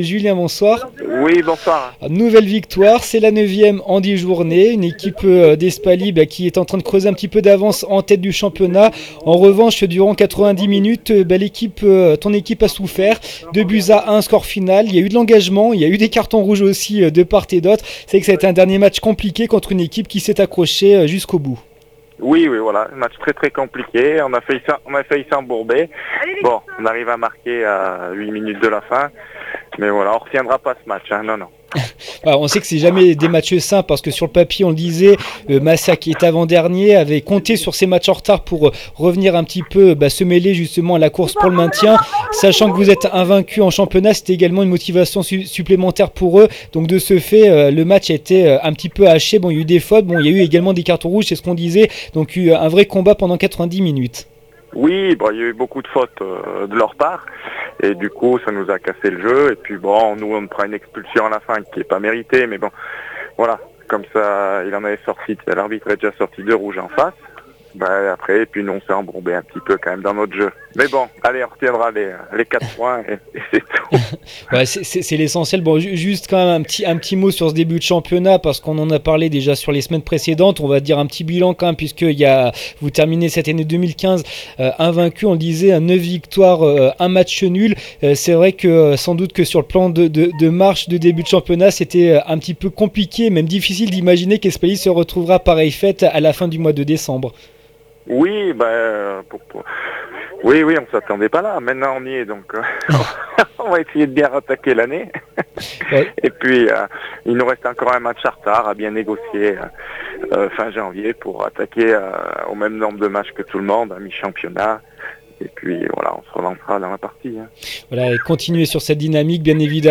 MATCH DE FOOT DIVISION HONNEUR REGIONALE DU 20/12/15 FC ESPALY – MASSIAC 2-1 REACTION